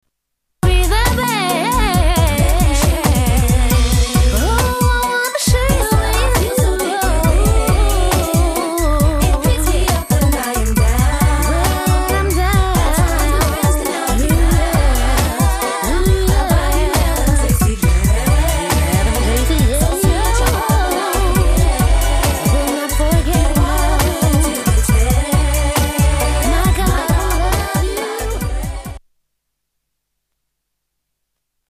The trio of soul sisters with a great line in gospel garage
Style: Gospel